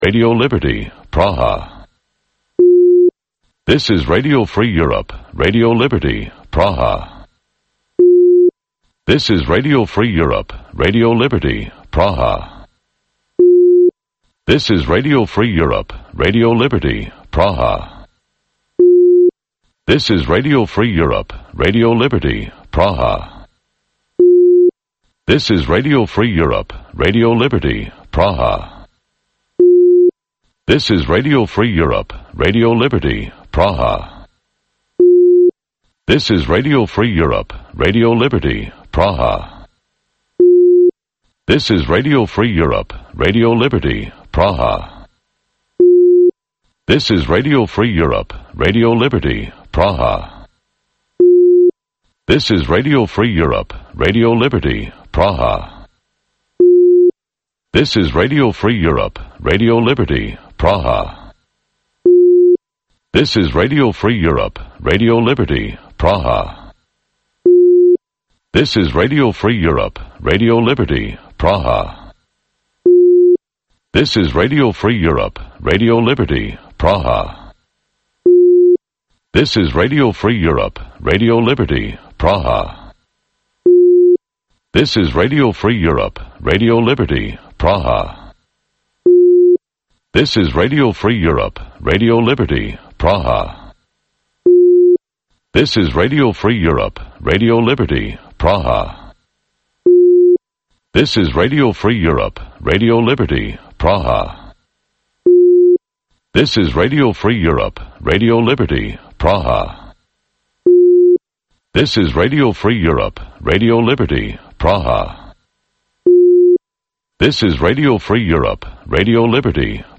Гуфтугӯи ошкоро бо афроди саршинос ва мӯътабари Тоҷикистон.